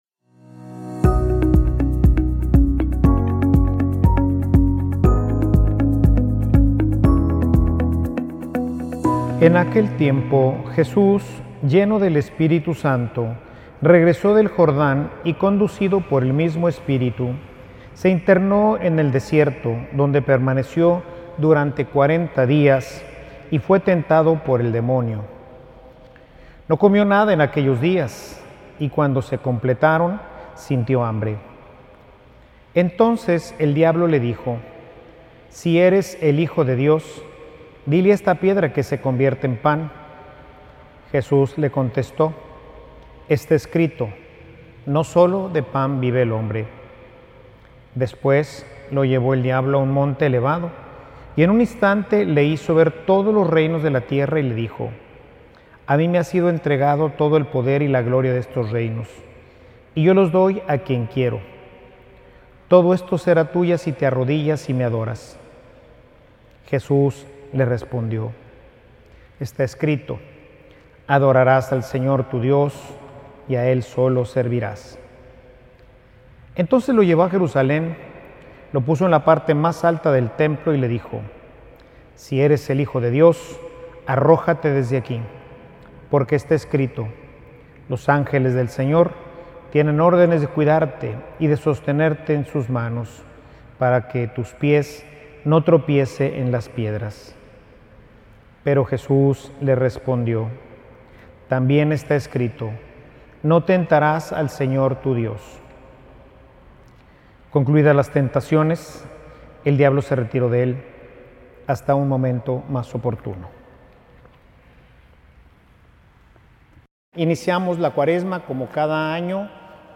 Homilia_La_vida_necesita_desierto.mp3